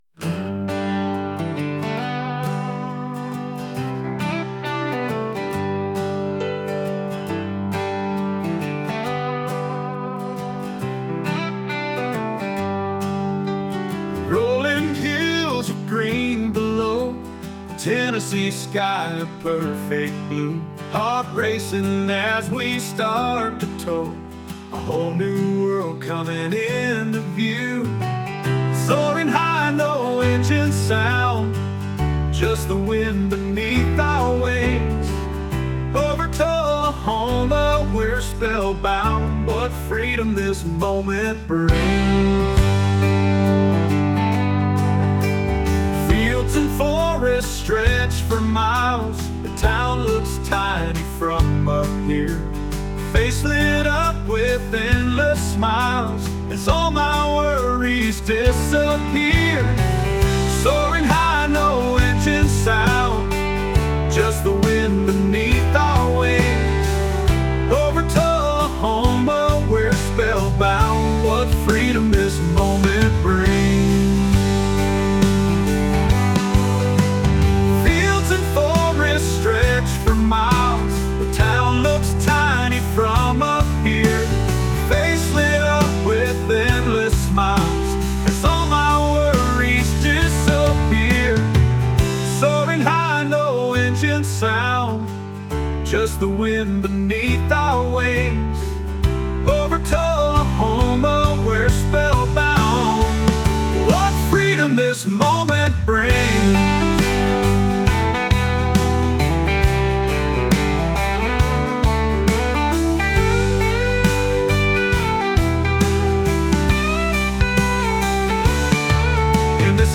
he got an assist from artificial intelligence (AI).
Musical influences for “Gliding Over Tullahoma” were piano blues, Southern rock, folk, and Americana.